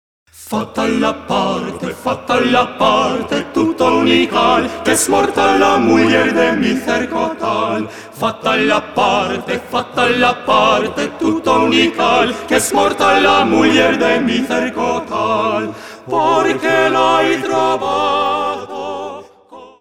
madrigals composed during the Renaissance
This is vocal music that belongs to the soul.